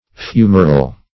fumerell - definition of fumerell - synonyms, pronunciation, spelling from Free Dictionary Search Result for " fumerell" : The Collaborative International Dictionary of English v.0.48: Fumerell \Fu"mer*ell\, n. (Arch.)